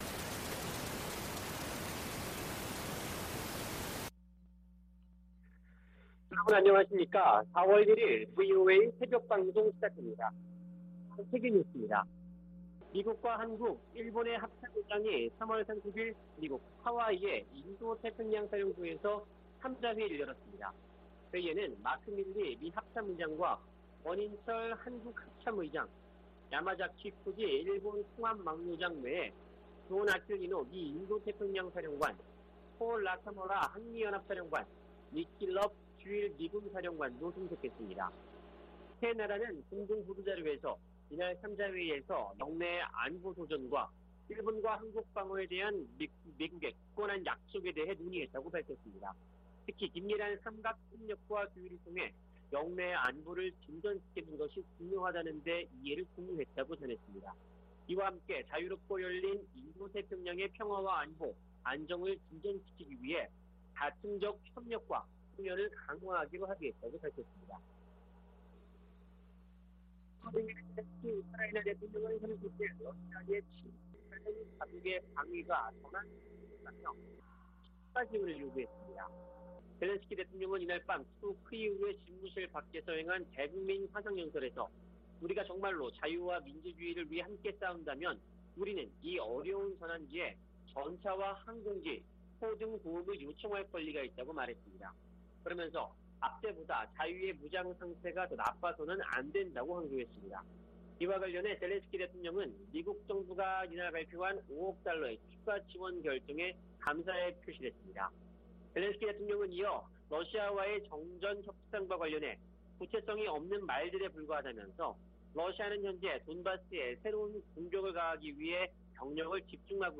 VOA 한국어 '출발 뉴스 쇼', 2022년 4월 1일 방송입니다. 북한이 최근 ICBM을 발사한 곳이 평양 순안공항 인근 미사일 기지에서 멀지 않은 곳으로 확인됐습니다. 북한이 최근 발사한 ICBM이 화성 17형이 아닌 화성 15형이라고 한국 국방부가 밝힌 가운데 미 당국은 여전히 분석 중이라는 입장을 내놨습니다. 북한에서 6개월 안에 식량상황 악화 등 인도주의적 위기가 발생할 가능성이 있다고 스위스 비정부기구가 전망했습니다.